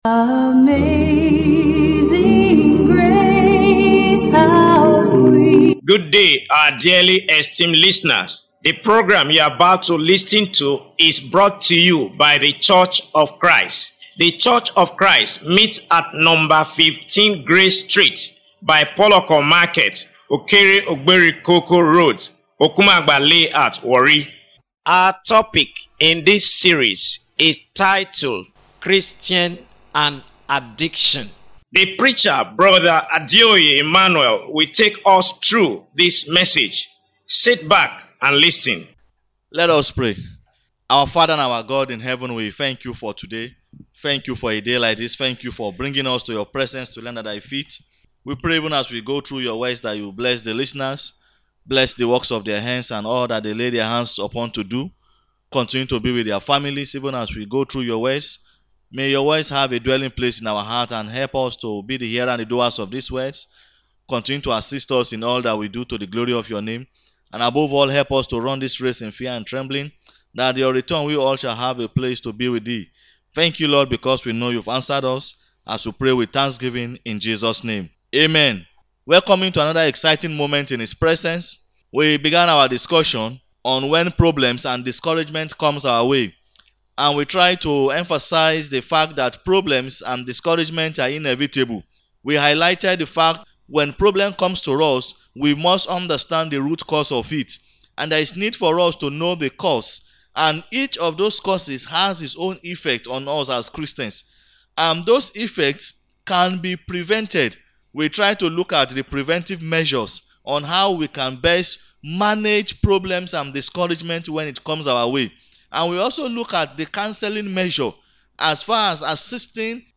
Radio Evangelism